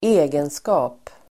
Uttal: [²'e:genska:p]